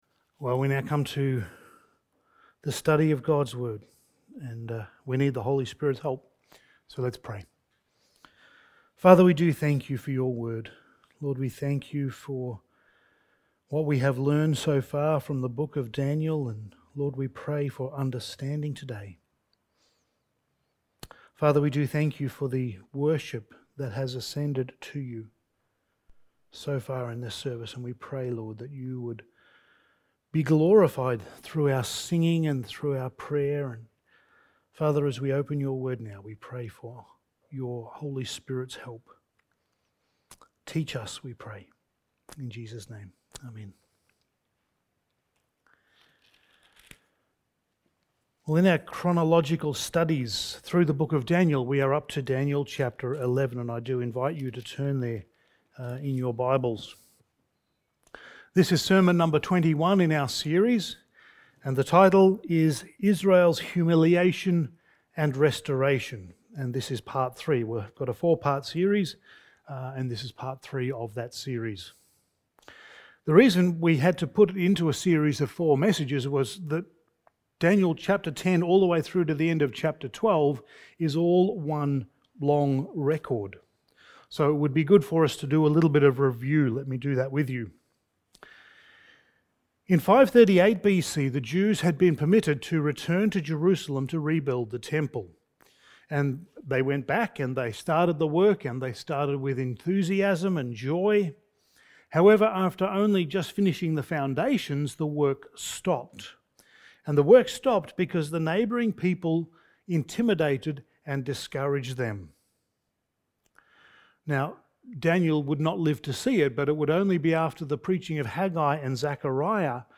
Passage: Daniel 11:36-45 Service Type: Sunday Morning